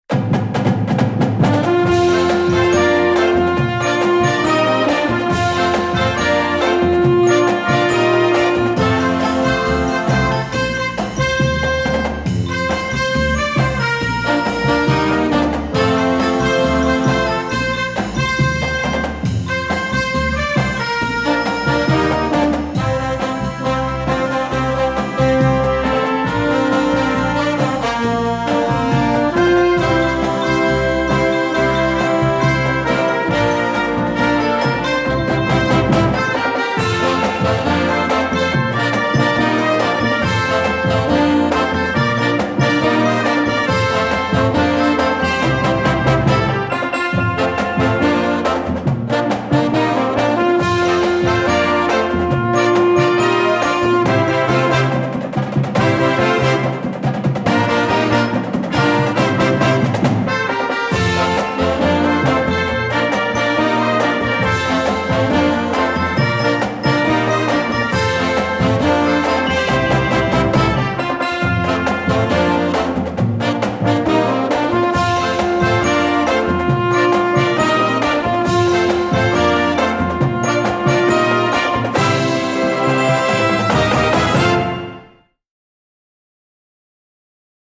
Instrumental Marching Band 70's, 80's & 90's
It's classic rock at its best!